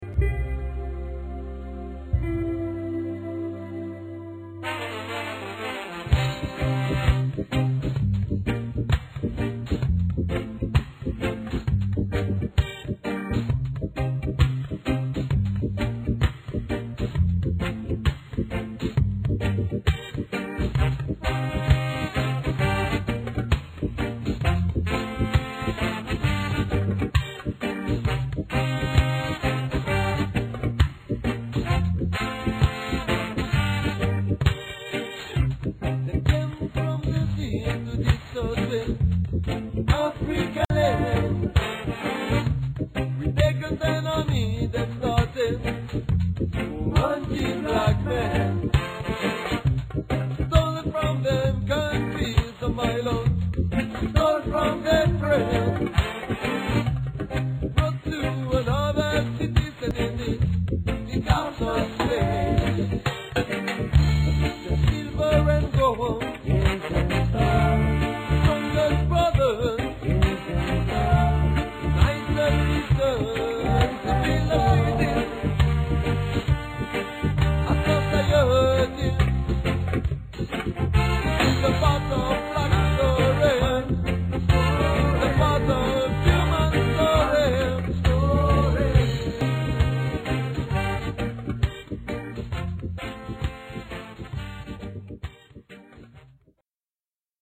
French reggae band